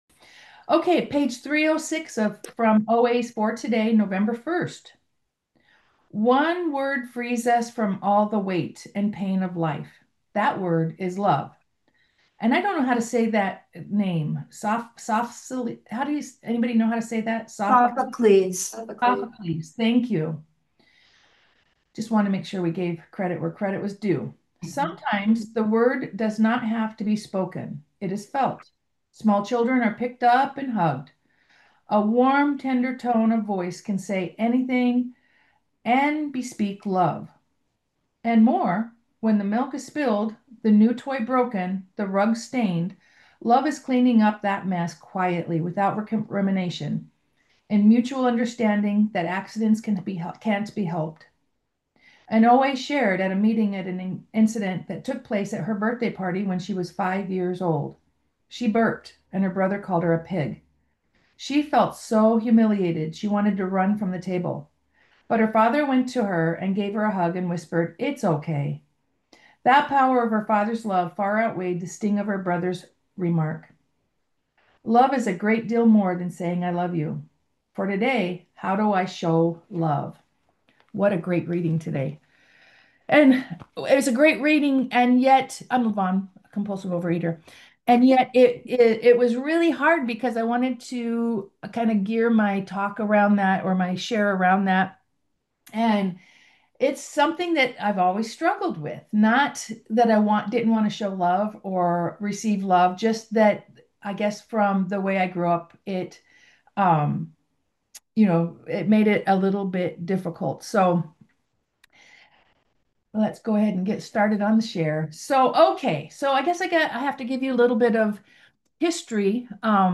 Members of OA SGVIE share their experience strength and hope.